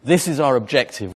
For comparison, here are examples of objÉctive used by natives: